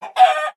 chicken_hurt1.ogg